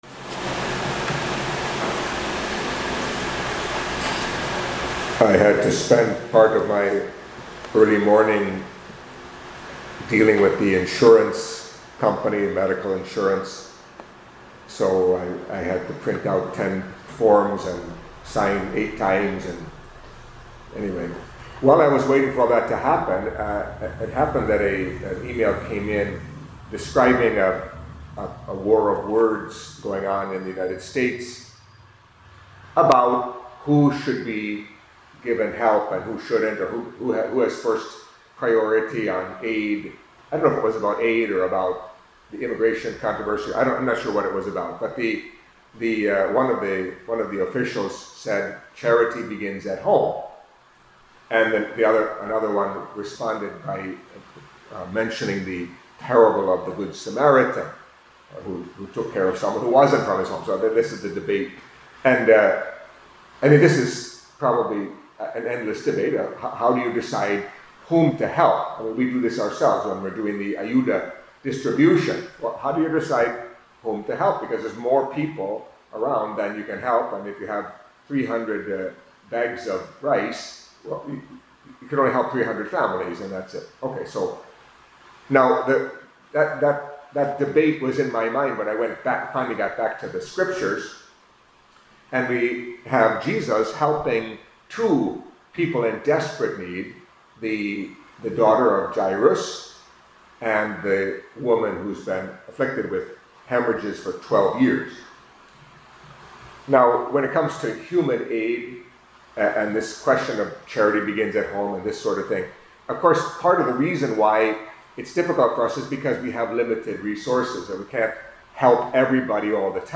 Catholic Mass homily for Tuesday of the Fourth Week in Ordinary Time